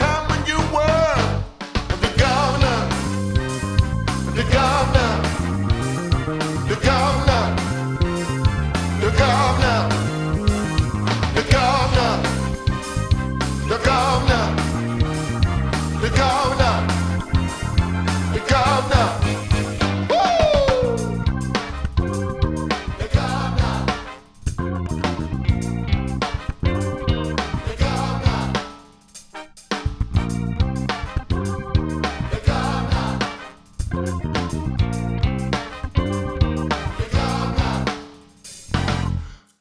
両耳で使用すると　ステレオで聞けますので
ドラム・モニター音源